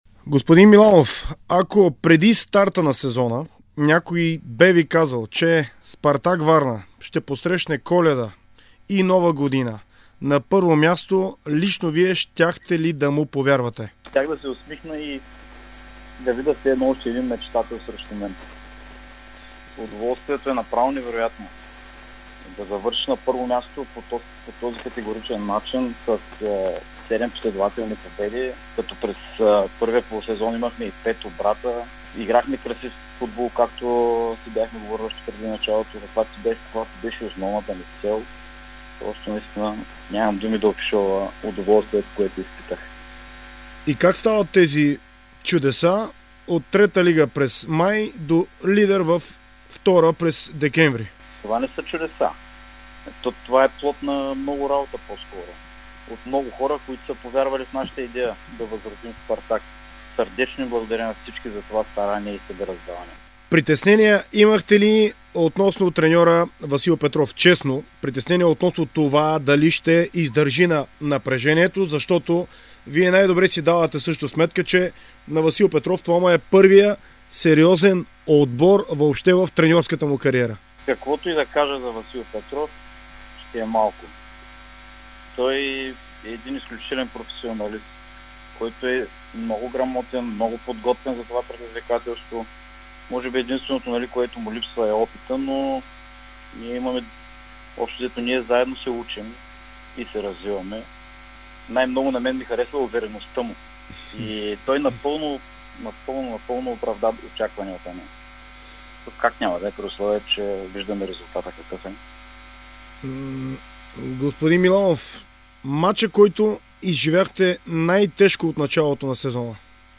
В интервю за Дарик радио и dsport той направи равносметка за представянето на "соколите" до тук, разкри, че в клуба вече мислят за осветление и ел. табло. Той сподели, че ако Спартак влезе в Първа лига, ще продължи да играе атакуващ футбол.